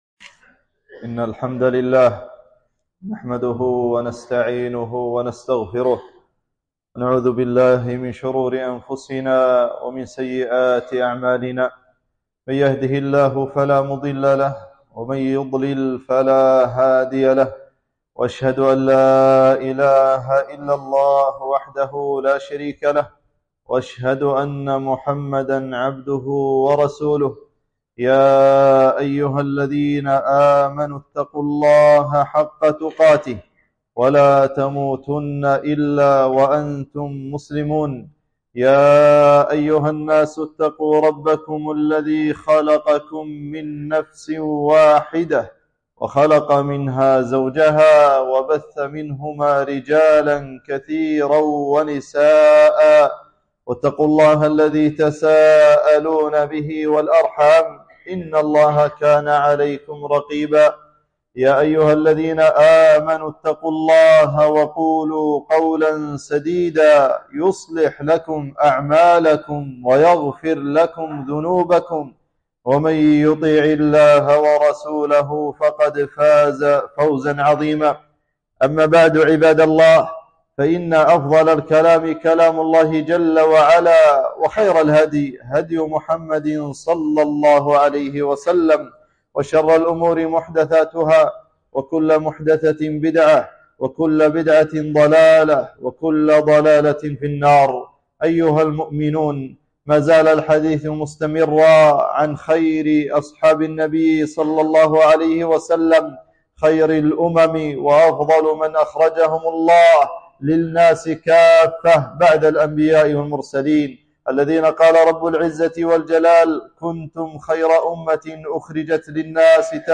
خطبة - فضائل عمر الفاروق